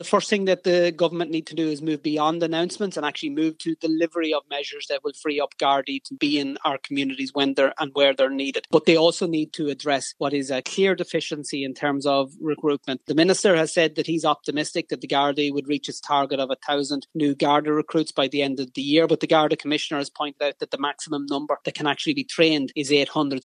But Sinn Féin’s Justice Spokesperson, Matt Carthy, says more gardaí need to be trained: